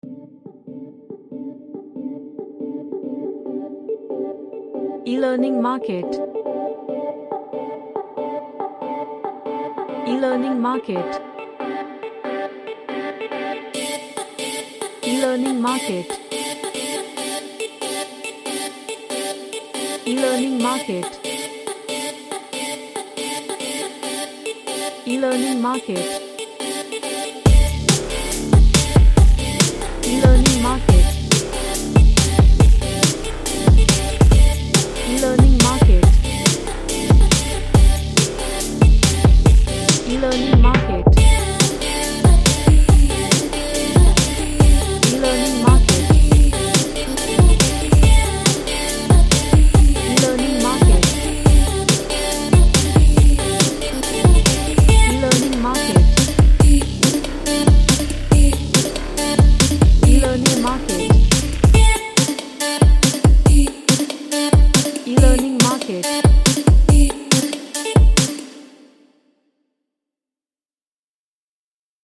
A vocalic electronic track
Sci-Fi / Future